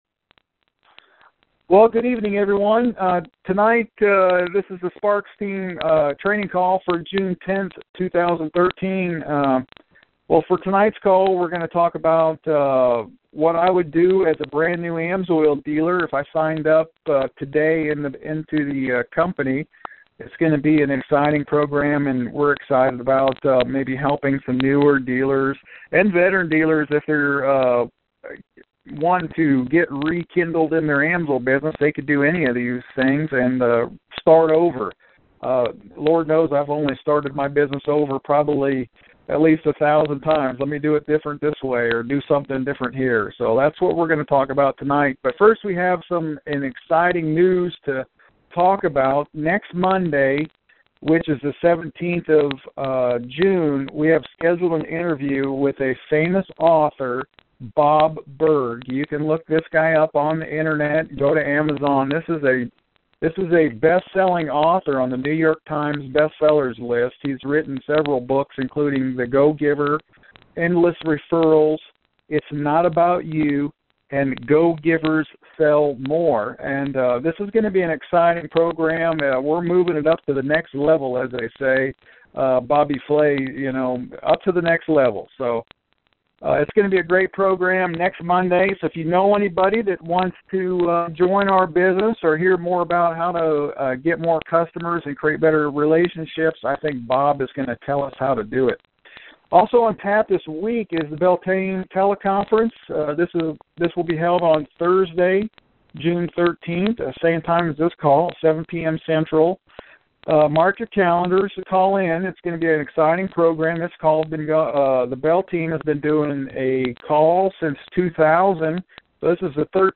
Team Training Call